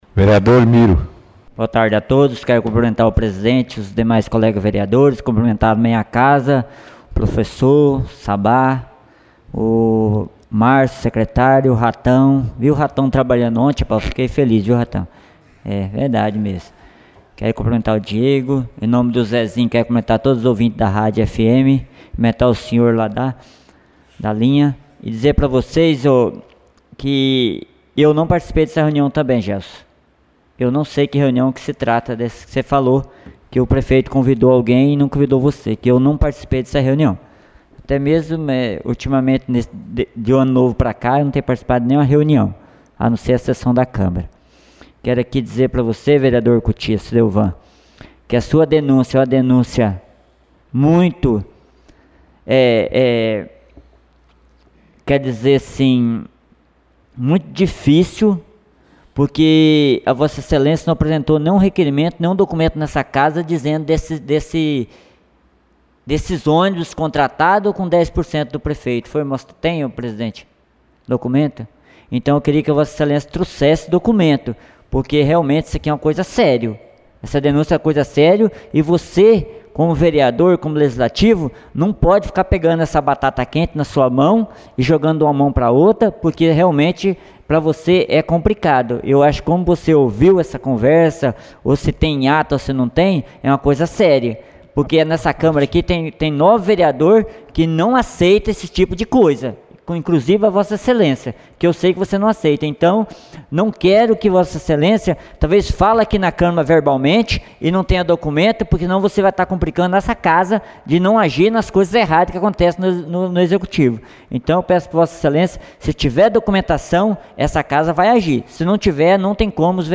69ª Sessão Ordinária da 7ª Legislatura